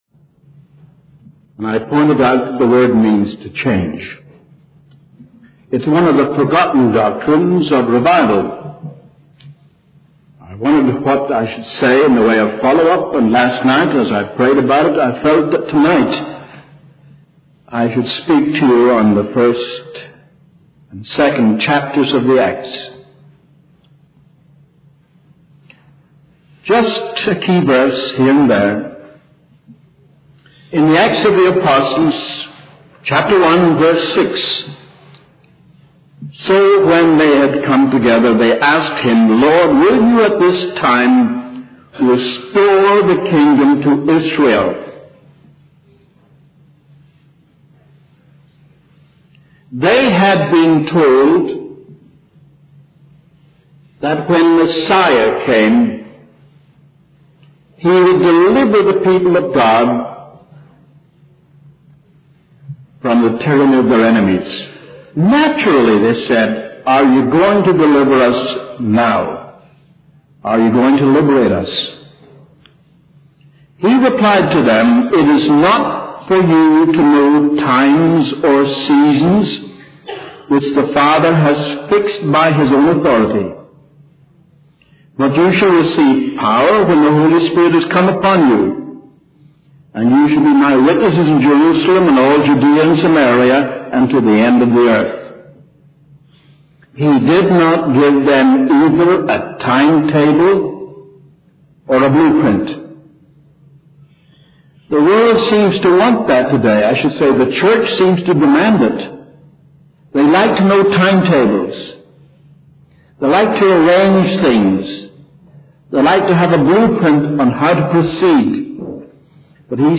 In this sermon, the speaker emphasizes the need for an outpouring of the Holy Spirit upon the entire body of Christ and the conviction of non-believers. He highlights the importance of prayer and the Holy Spirit's role in bringing about revival.